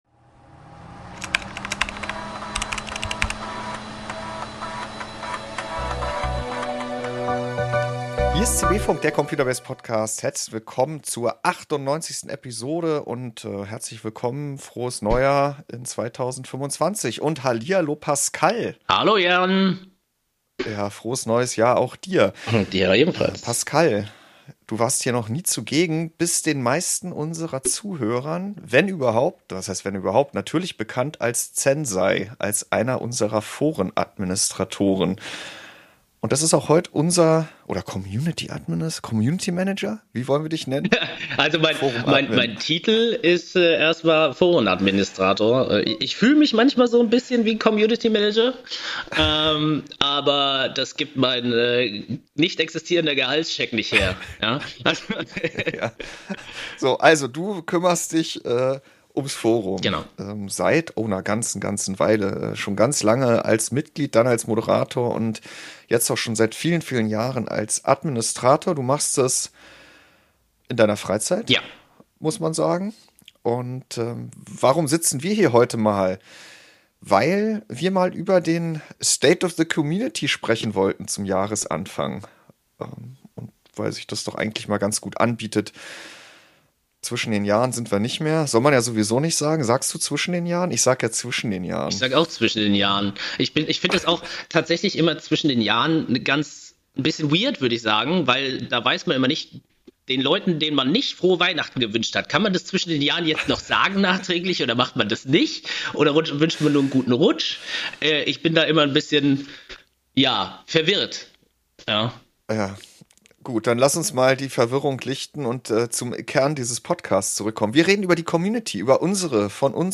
Die Redaktion von ComputerBase diskutiert im CB-Funk ganz ohne Gebrüll aktuelle Themen und gewährt Einblicke hinter die Kulissen: Warum hat die Redaktion wie berichtet oder getestet, was steht an, oder wo klemmt es?